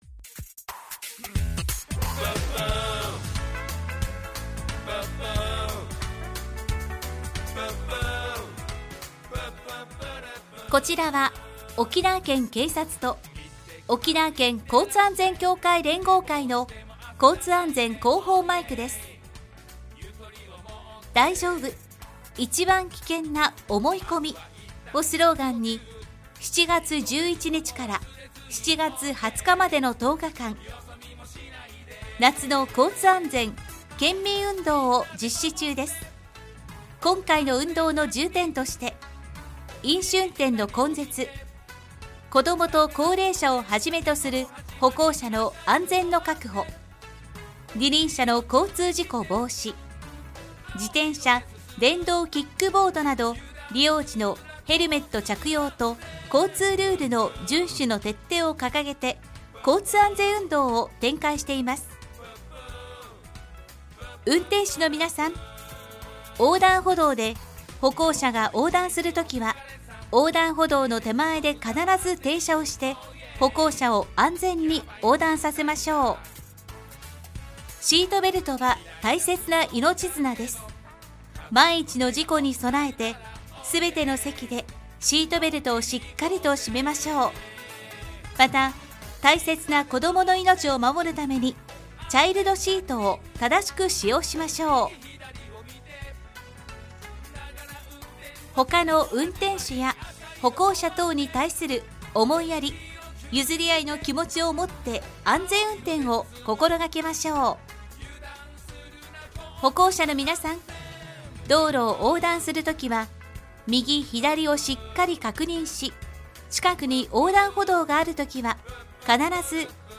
令和7年　夏の交通安全県民運動広報音声